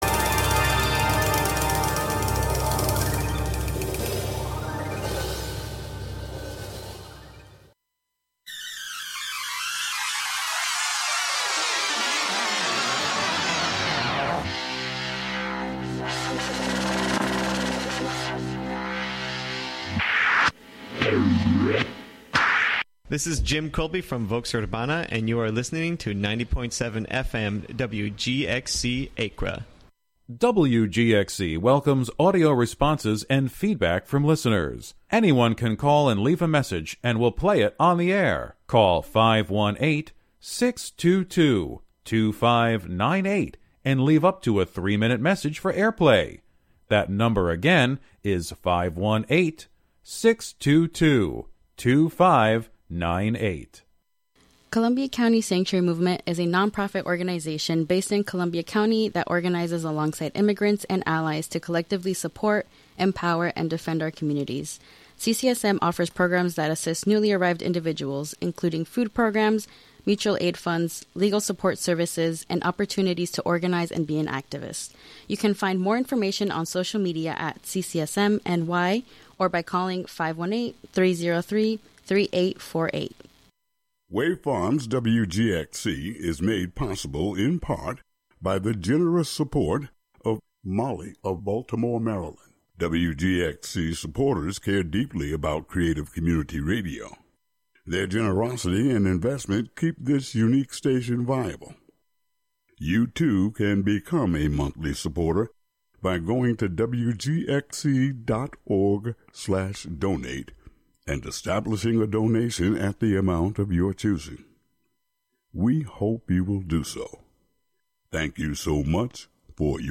It also amplifies Indigenous music traditions to bring attention to their right to a sustainable future in the face of continued violence and oppression. We will explore the intersection of acoustic ecology, musical ethnography, soundscape studies, and restorative listening practices from the perspective of Indigenous musical TEK (Traditional Ecological Knowledge).